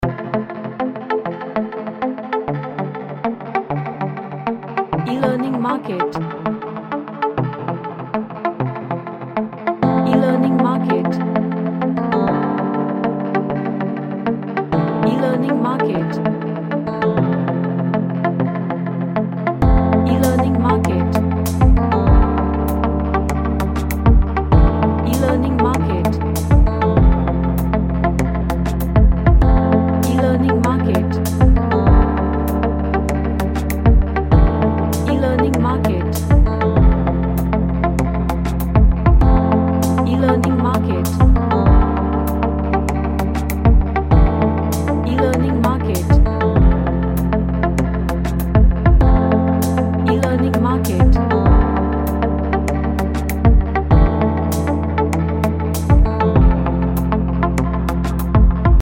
A retro type ambient track.
Emotional